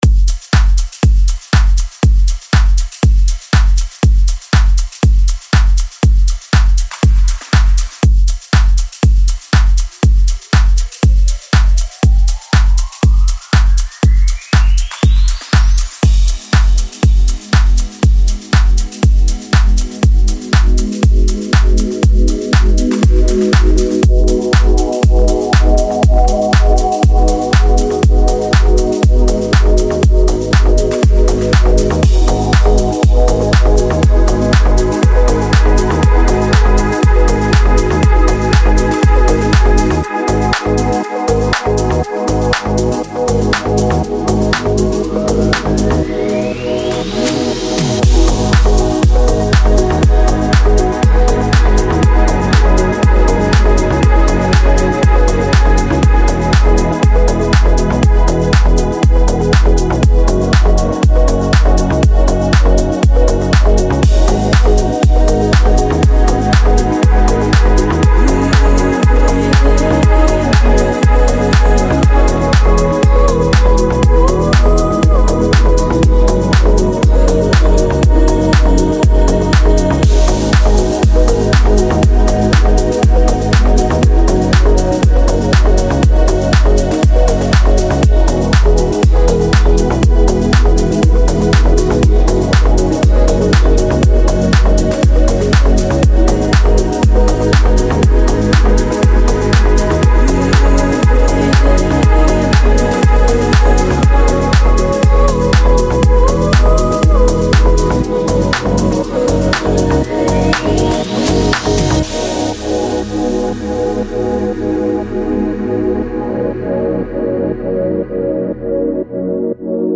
Deep House House